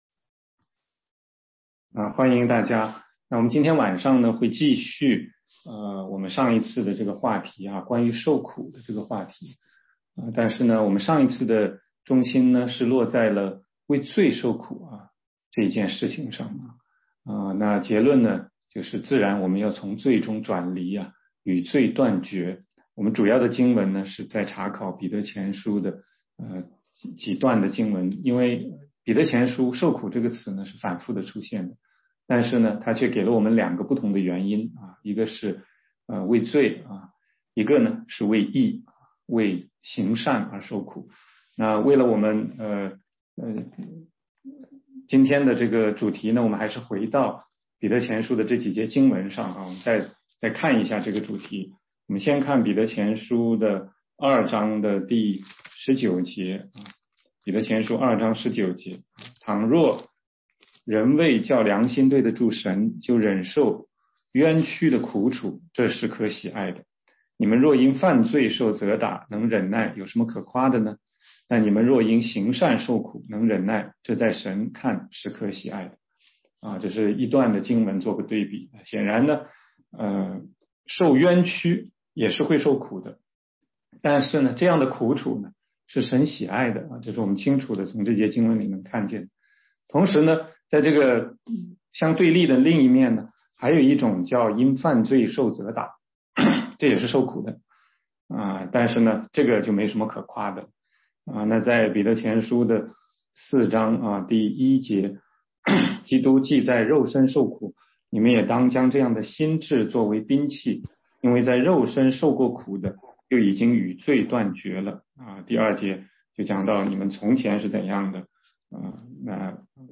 16街讲道录音 - 基督徒成圣的第二阶段：为义受苦（全中文）
中英文查经